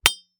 Sword Hit Wood Dull Sound
weapon